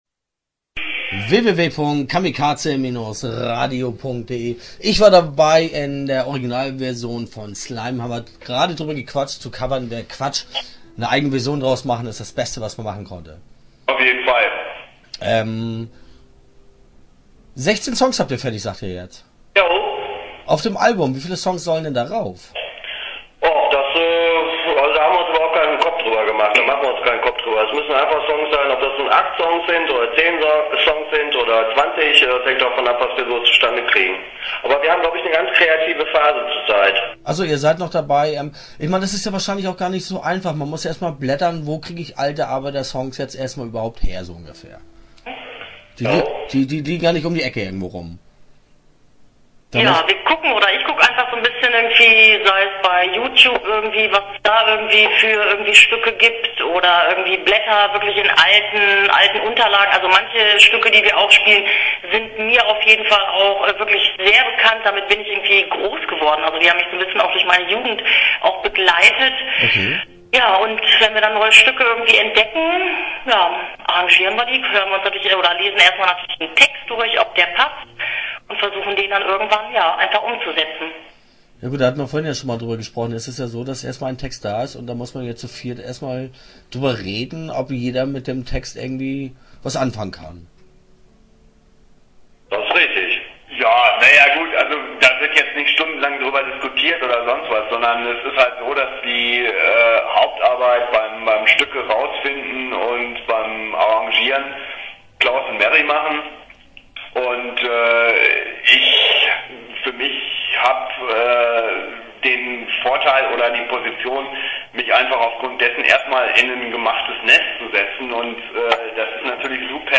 Interview Teil 1 (11:29)